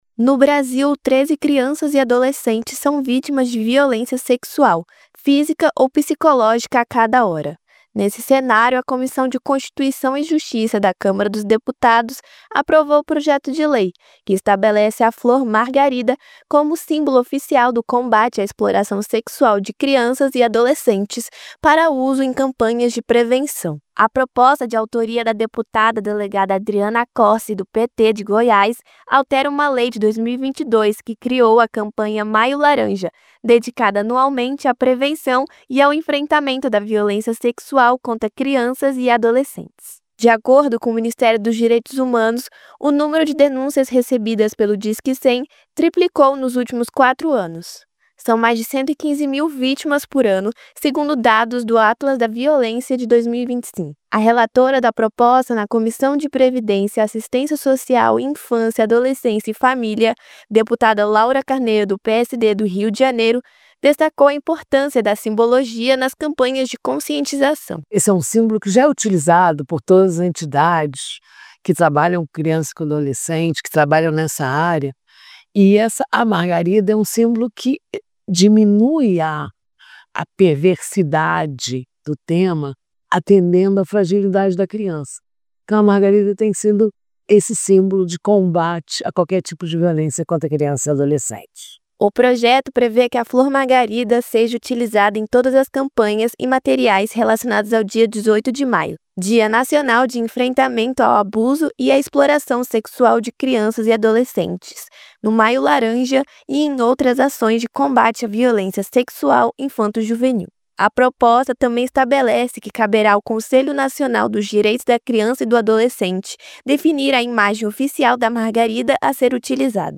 COMISSÃO APROVA USO DA MARGARIDA COMO SÍMBOLO NO COMBATE À EXPLORAÇÃO SEXUAL DE CRIANÇAS E ADOLESCENTES. A REPÓRTER